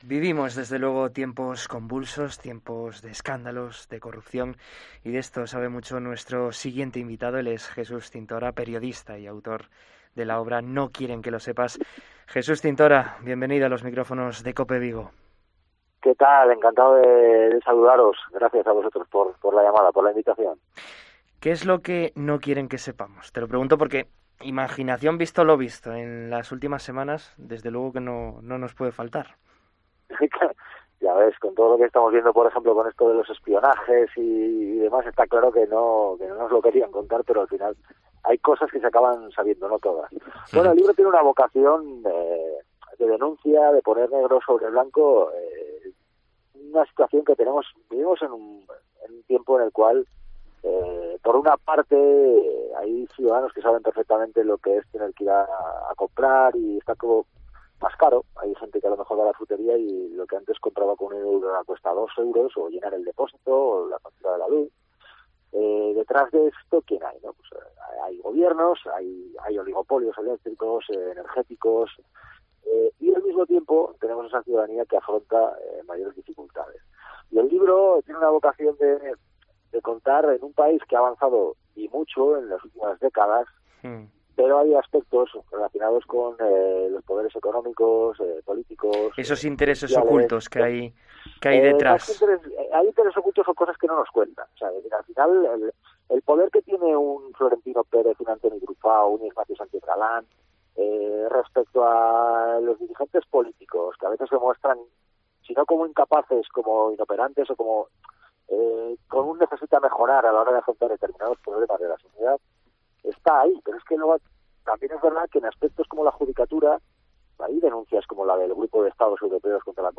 AUDIO: En COPE Vigo hablamos con Jesús Cintora, periodista y autor de 'No quieren que lo sepas'